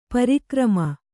♪ pari krama